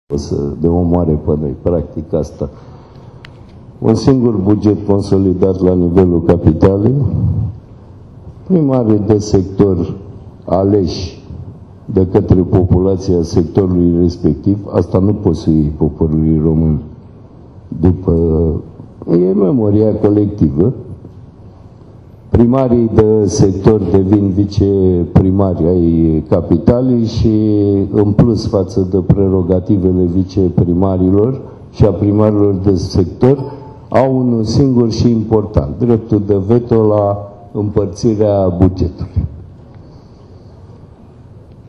Declarațiile au fost făcute la Forumul Național al Orașelor “Think City”.